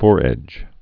(fôrĕj)